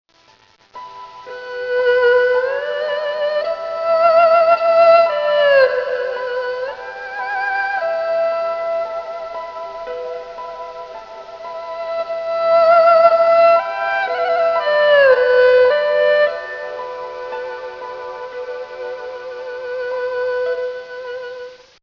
胡弓は、「人の声にもよく似ている」と言われるその音色は、伸びやかさと豊かな響きをもち、その表現力の深さは人を魅了してやまない大きな力を秘めております。
胡弓（二胡）の構造